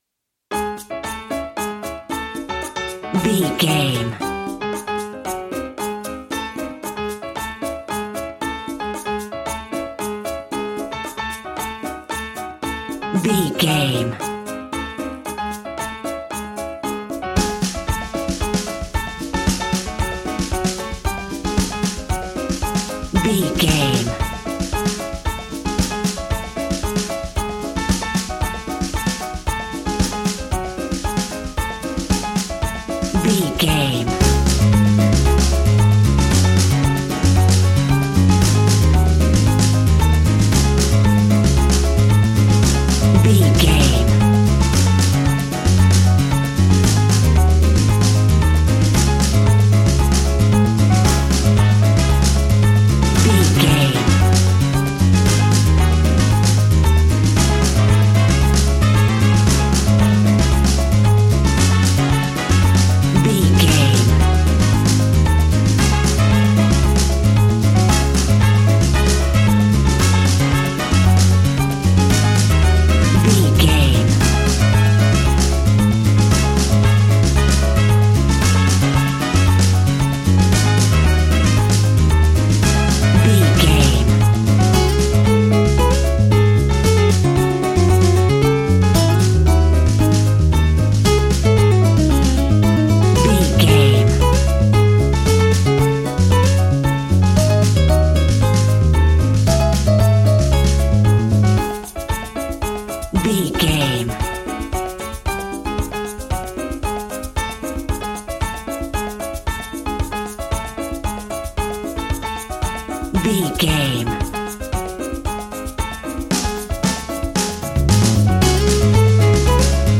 An exotic and colorful piece of Espanic and Latin music.
Aeolian/Minor
C#
maracas
percussion spanish guitar